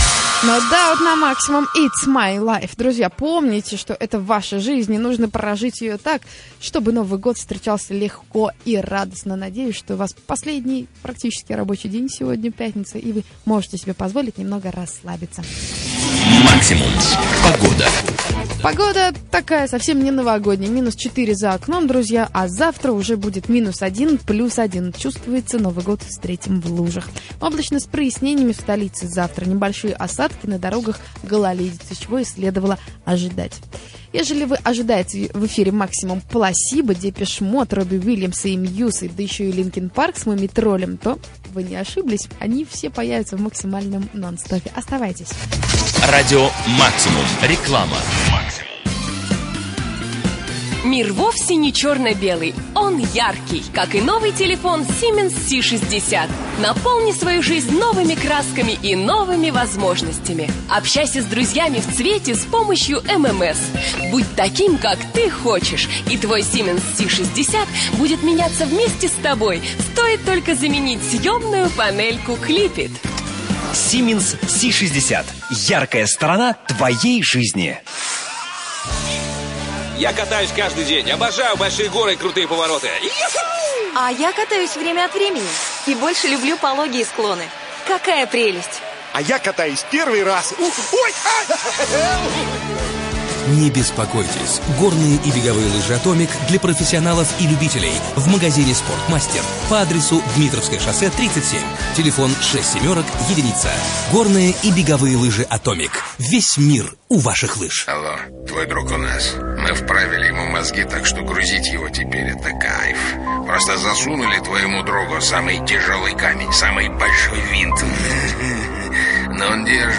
Диктор, погода и рекламный блок (Радио Максимум, 26.12.2003) Siemens, Atomic, Air, "В поисках Немо", Espri, Новогодняя ночь в hard-rock кафе